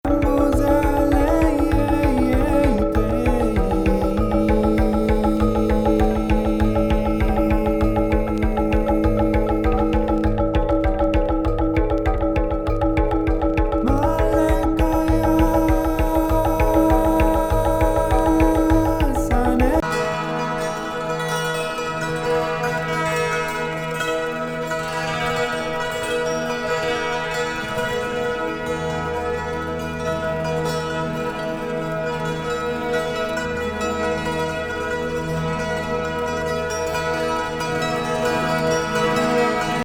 エジプトの謎のフルートやスパニッシュ・ギターに大量の植木鉢（？）などで演奏した幽玄のエスニック・ニューエイジ・ジャズ！